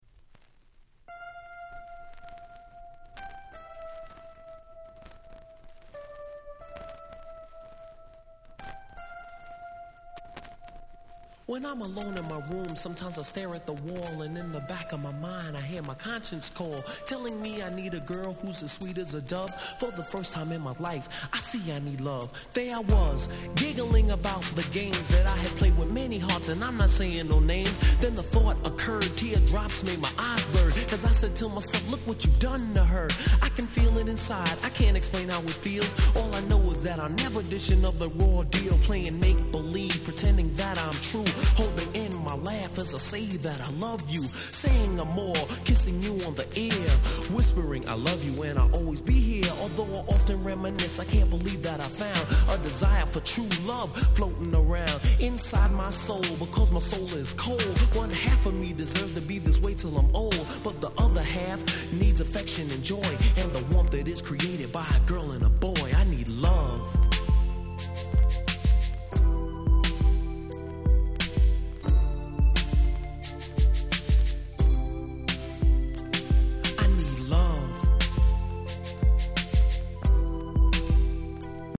90's 80's Boom Bap ブーンバップ Old School オールドスクール